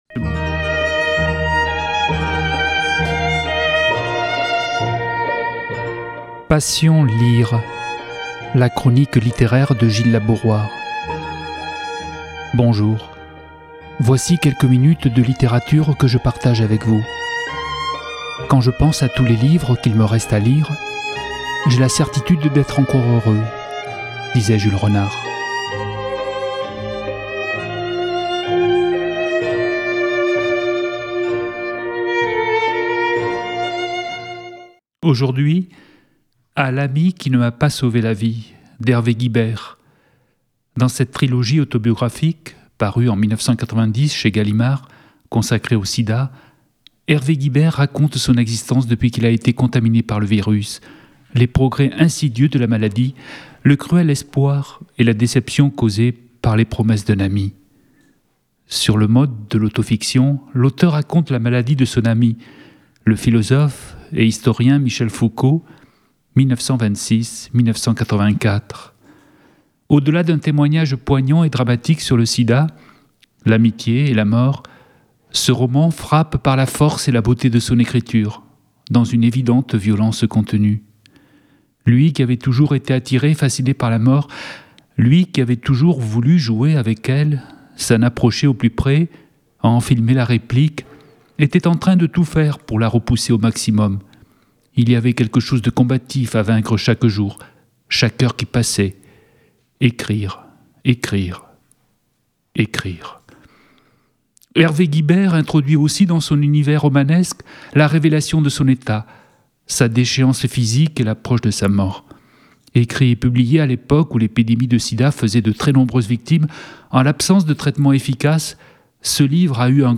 Une émission littéraire